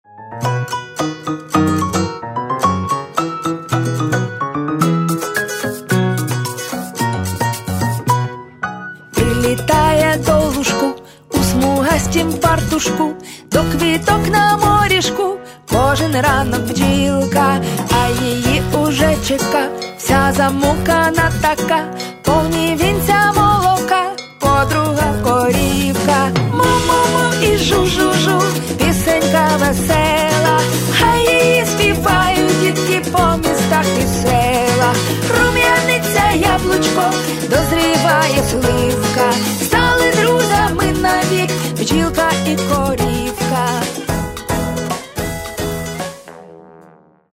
И нормальные интонации.